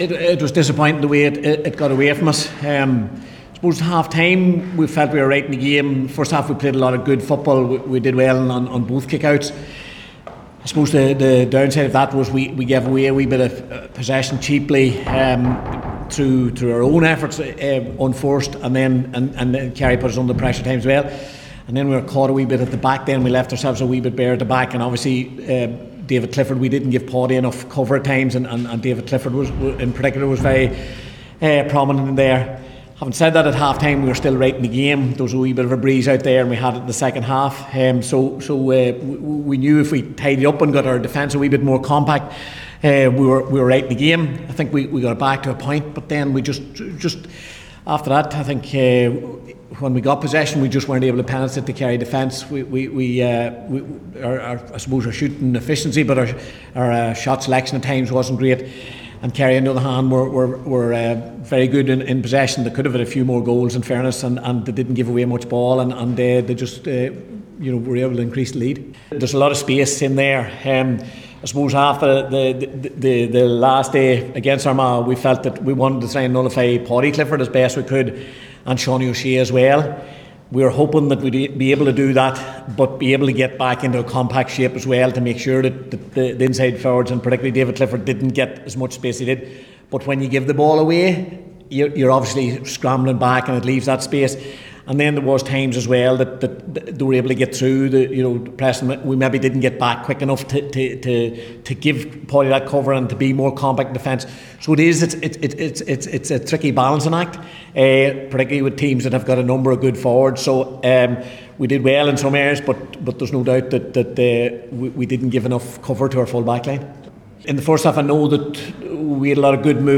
After the game, Tyrone boss Malachy O’Rourke said there are a lot of fires to put out in Kerry’s attack…